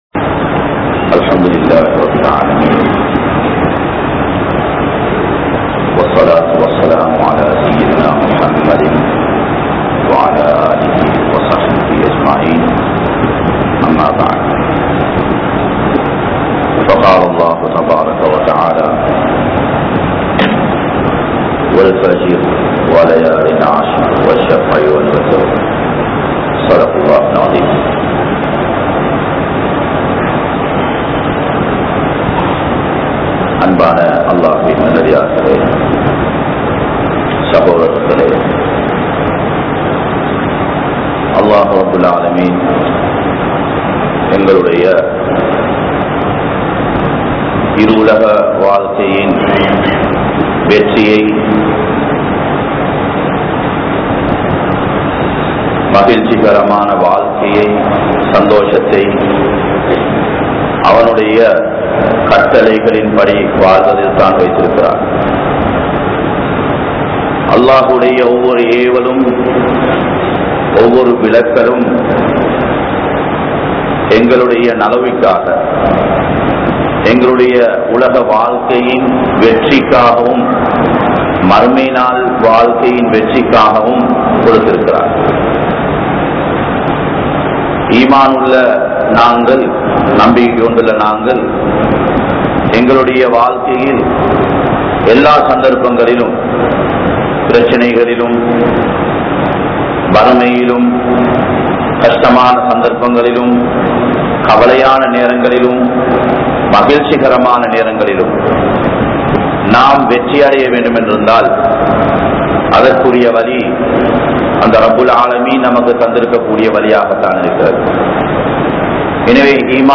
Dhull Hijjah Maathathin Sirappuhal | Audio Bayans | All Ceylon Muslim Youth Community | Addalaichenai